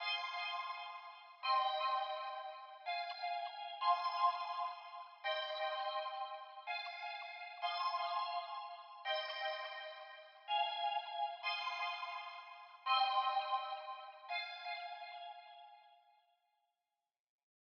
Souf 126 Pad.wav